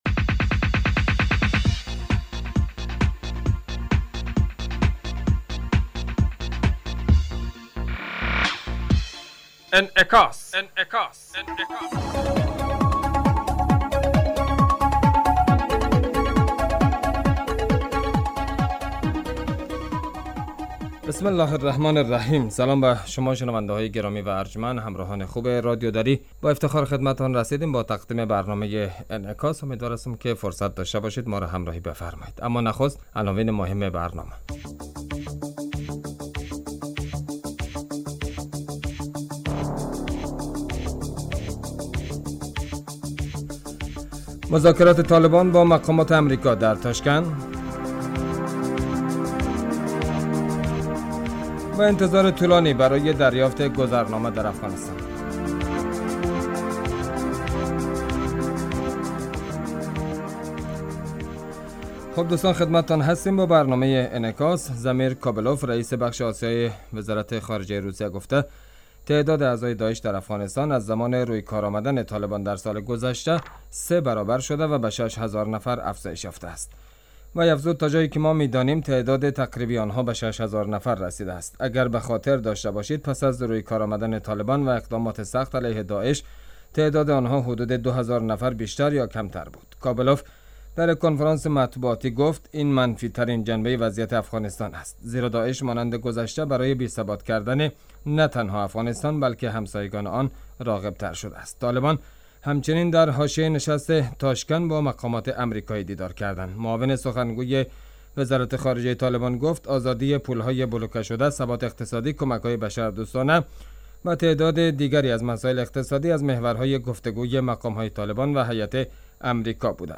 برنامه انعکاس به مدت 35 دقیقه هر روز در ساعت 18:50 بعد ظهر بصورت زنده پخش می شود. این برنامه به انعکاس رویدادهای سیاسی، فرهنگی، اقتصادی و اجتماعی مربوط به افغانستان و تحلیل این رویدادها می پردازد.